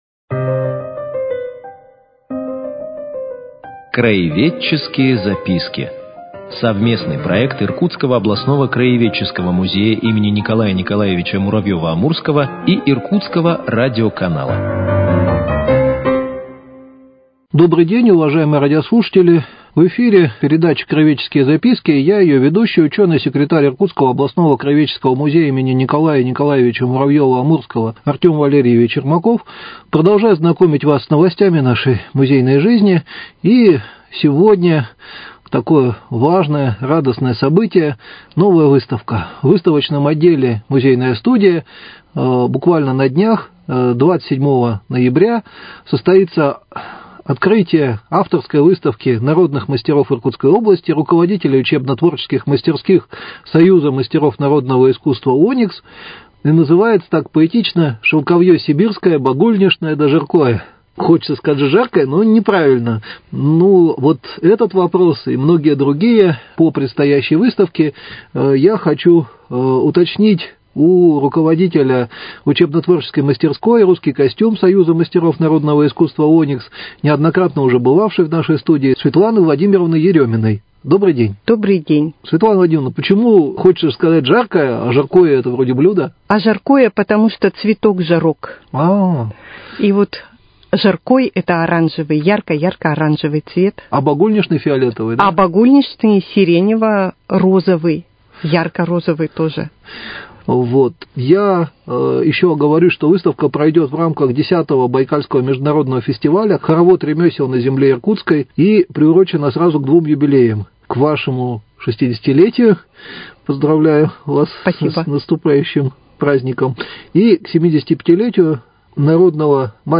Цикл передач – совместный проект Иркутского радио и Иркутского областного краеведческого музея им. Н.Н.Муравьёва - Амурского.